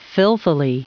Prononciation du mot filthily en anglais (fichier audio)
Prononciation du mot : filthily